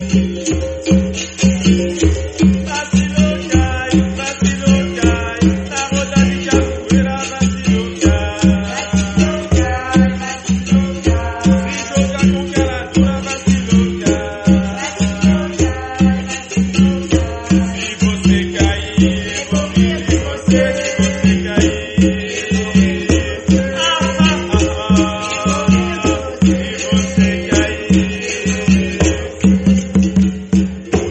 chants de capoeira
Le berimbau, instrument emblématique en forme d’arc, guide le rythme. Autour de lui, les pandeiros, atabaques et agogôs complètent l’ensemble.
Elle se déroule dans une roda, un cercle humain où les participants chantent, frappent dans les mains, et créent une atmosphère presque rituelle.